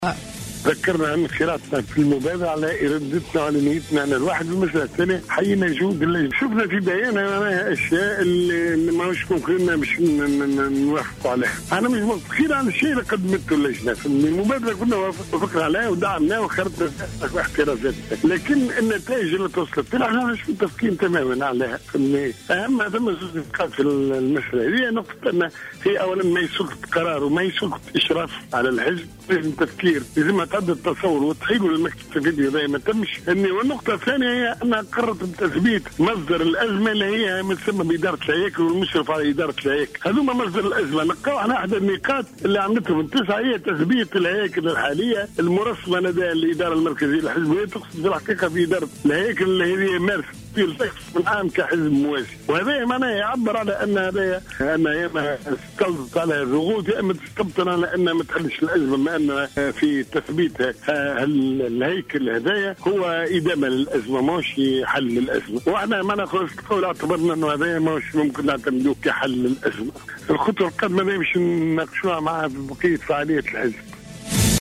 أكدت مجموعة النواب الاثنين والثلاثين الذين كانوا لوحوا بالاستقالة من حركة نداء تونس اثر اجتماعهم اليوم عدم موافقتهم على نتائج عمل اللجنة التي كلفها رئيس الجمهورية، الباجي قايد السبسي، الرئيس المؤسس للنداء لوضع حد للخلافات التي تشق الحركة وفق ما صرح به لجوهرة اف ام عضو المجموعة مصطفى بن أحمد.